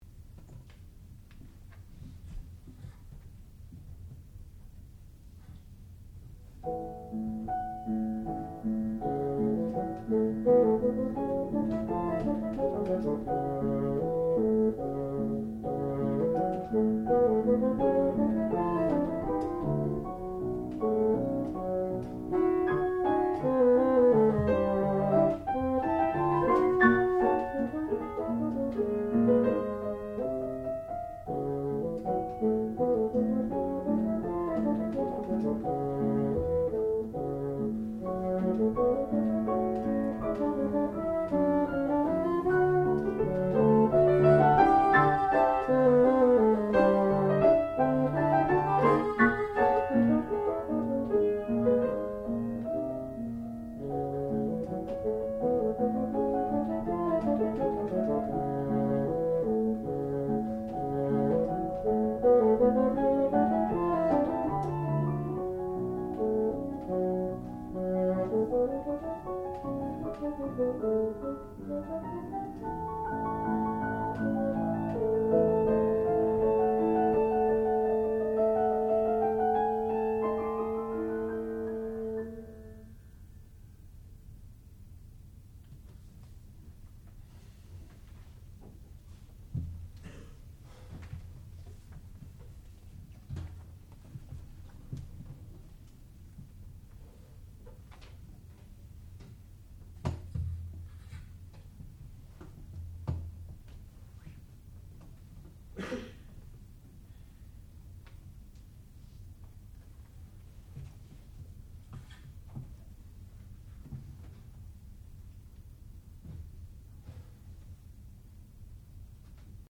classical music
piano
bassoon
Junior Recital